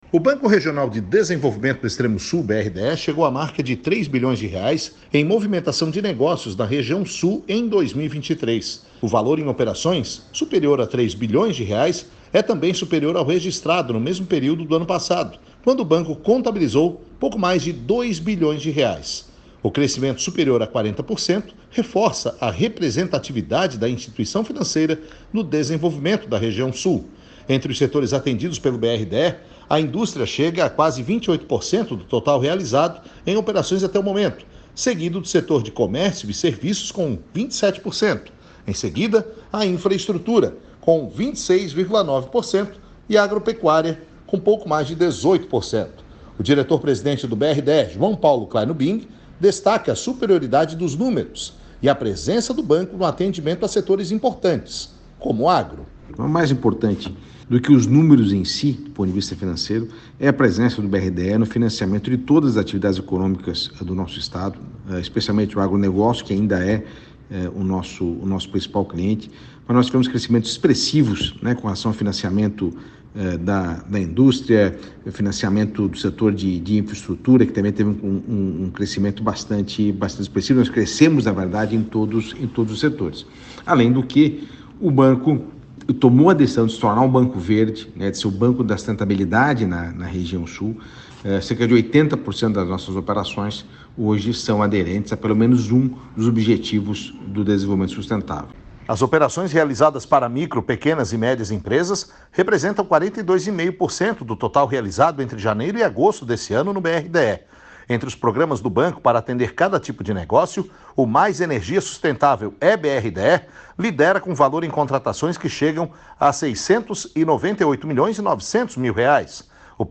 O Diretor Presidente do BRDE, João Paulo Kleinübing, destaca a superioridade dos números e a presença do banco no atendimento à setores importantes, como o agro: